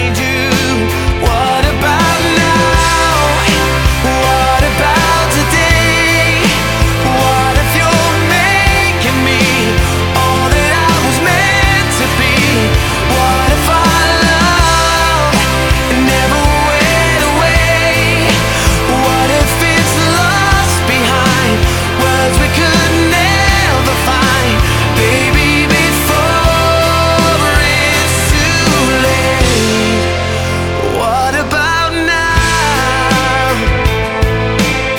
Жанр: Поп музыка / Рок / Танцевальные / Электроника